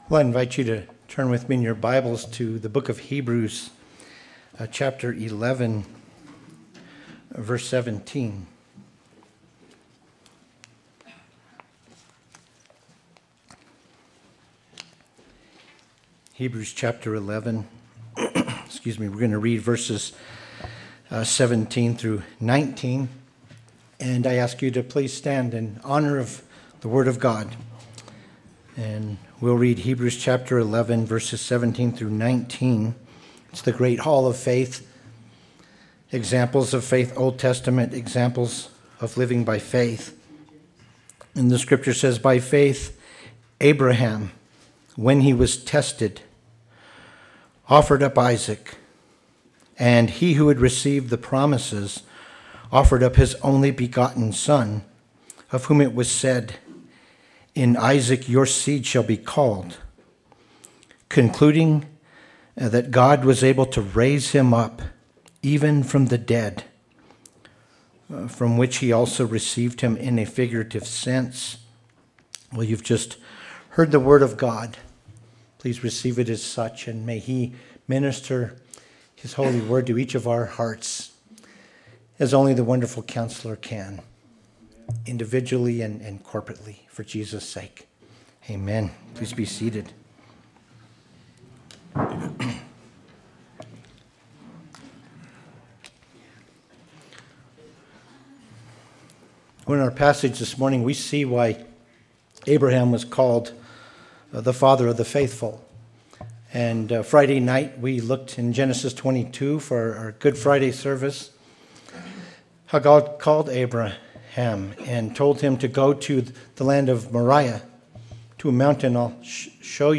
Good Friday Service and Communion